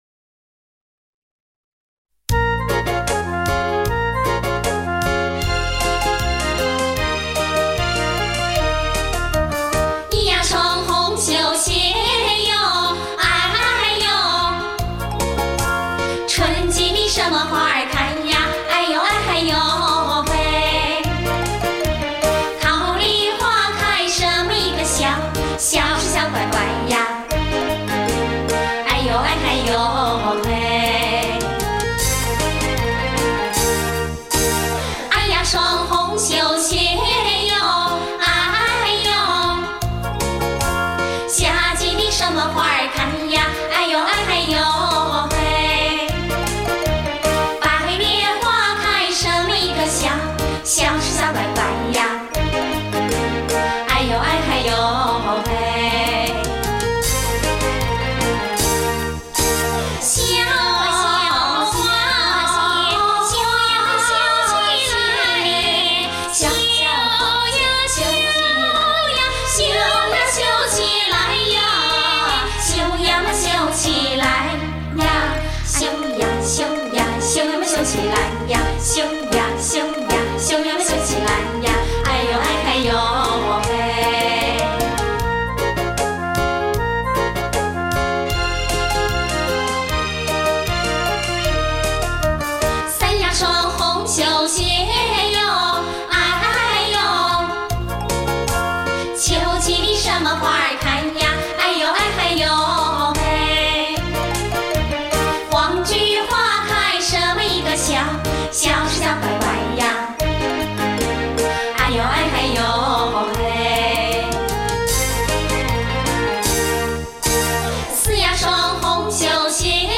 当前播放 四双红绣鞋（和县民歌）-001
民歌